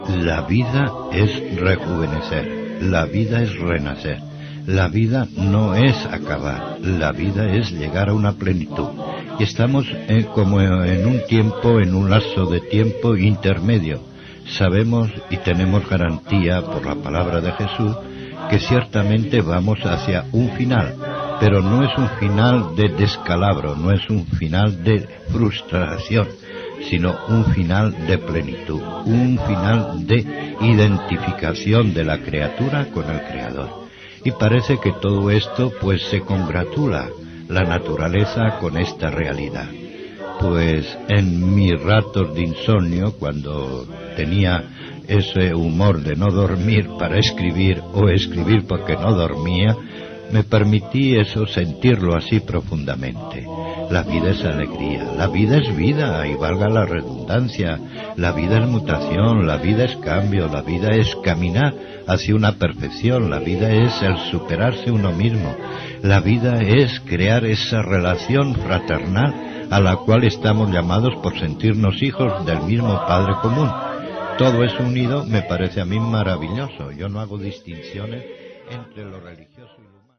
Religió
FM